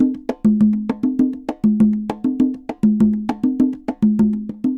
Congas_Samba 100_8.wav